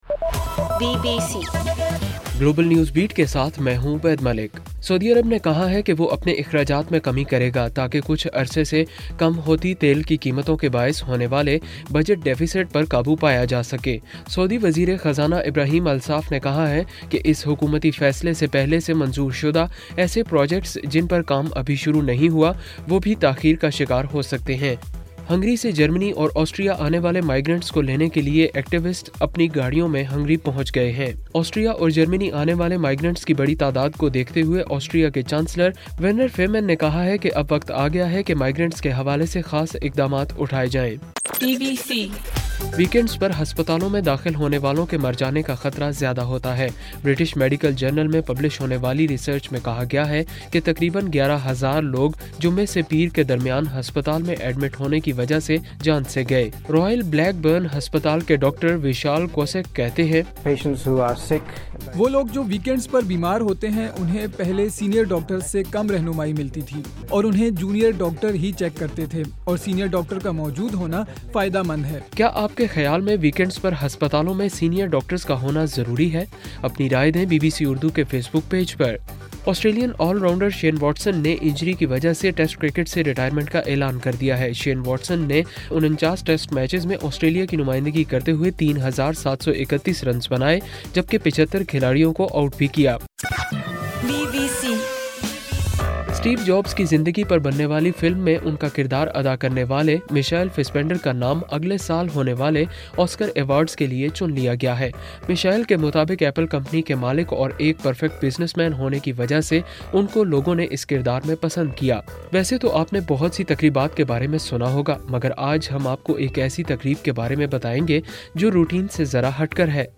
ستمبر 6: رات 10 بجے کا گلوبل نیوز بیٹ بُلیٹن